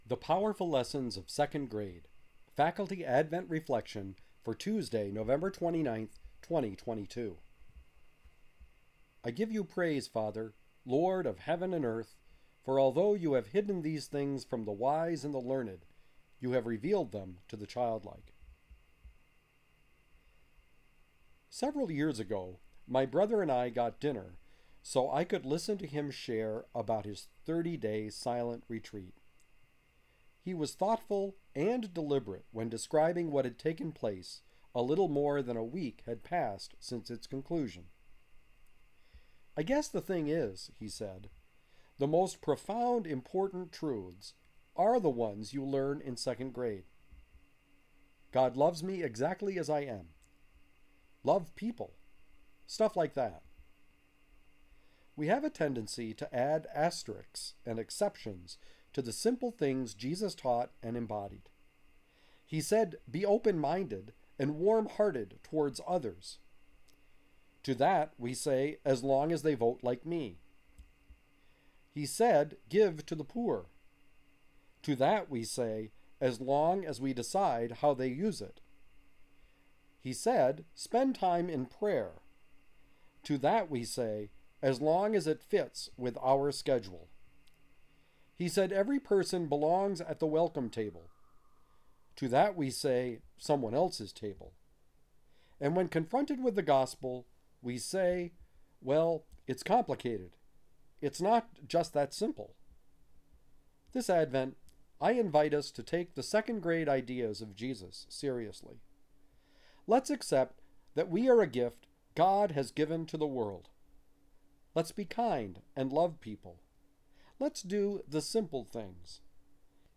Advent Reflection